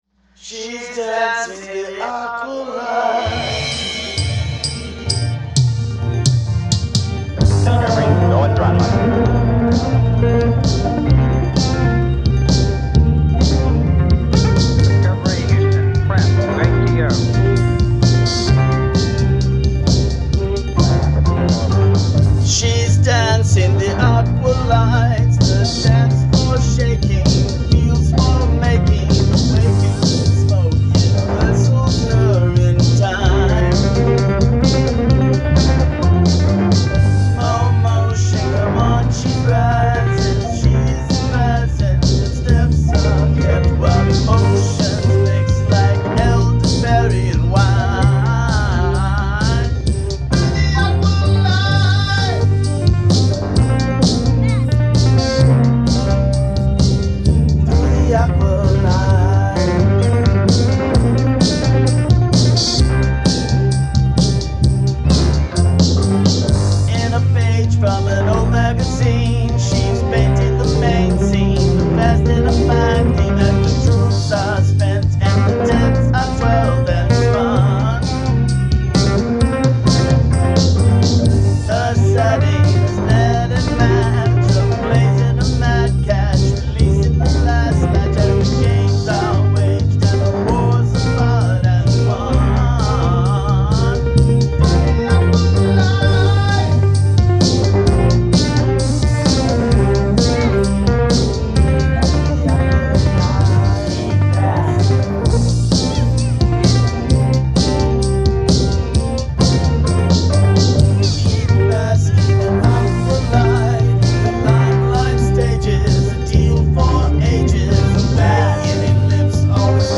Fresh out of the studio
Genre: Rock
Drums/Percussion
Bass
Guitar
Keyboard